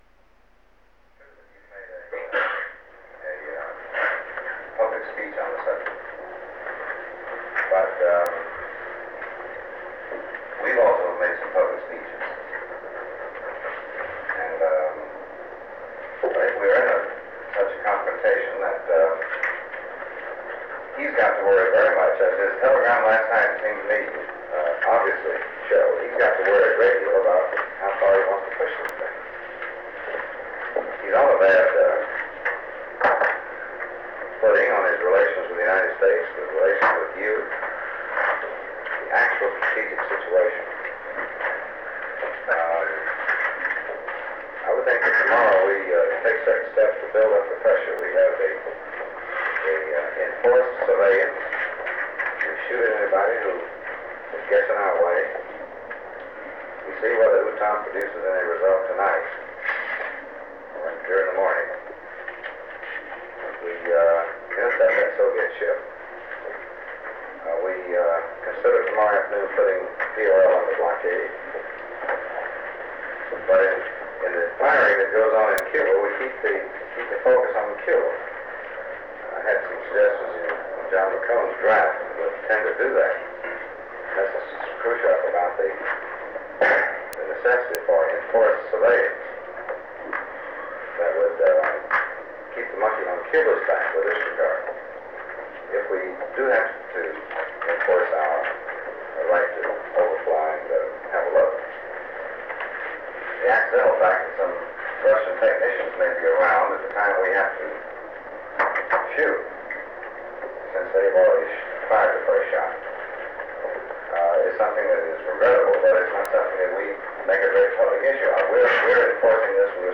Executive Committee Meeting of the National Security Council on the Cuban Missile Crisis
Secret White House Tapes